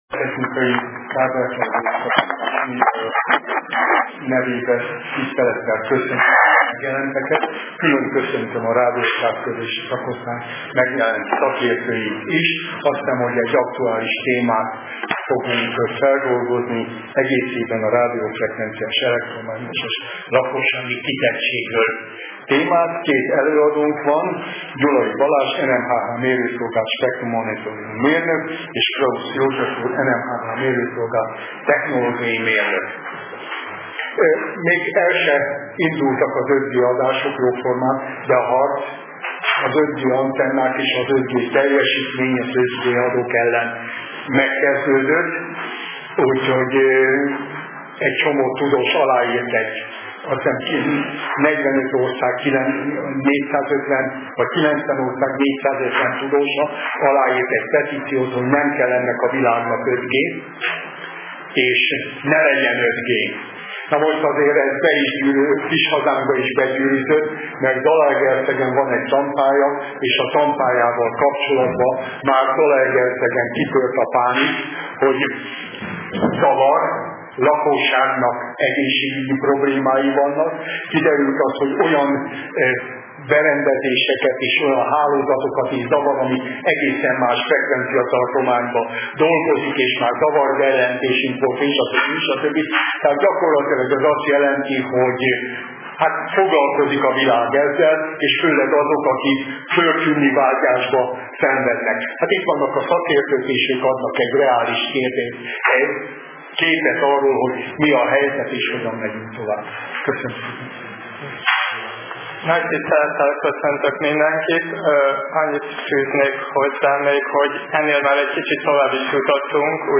A Vételtechnikai és a Kábeltelevíziós Szakosztály valamint a Médiaklub meghívja az érdeklődőket az alábbi igen érdekes és időszerű előadásra
Nézőink kérdezhetnek, hozzászólhatnak telefonon, továbbá regisztrált Youtube felhasználók az előadás alatt az üzenőfalon is hozzászólhatnak, kérdezhetnek.